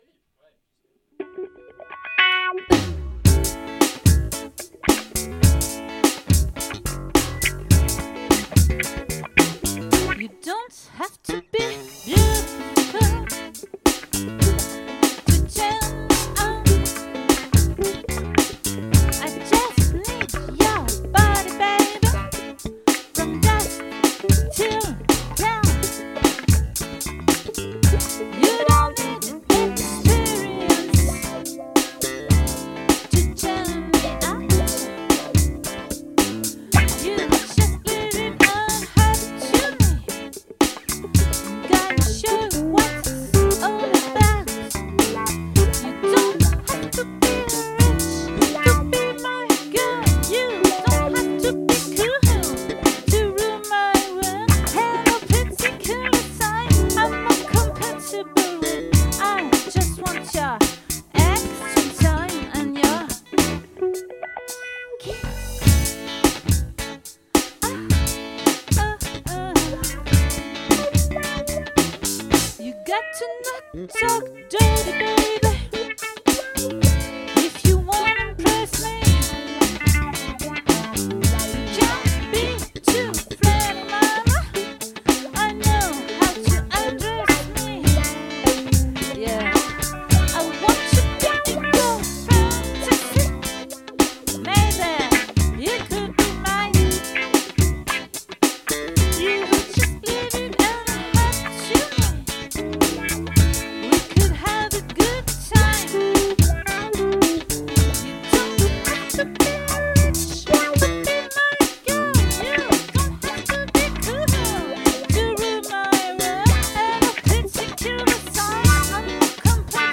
🏠 Accueil Repetitions Records_2022_06_29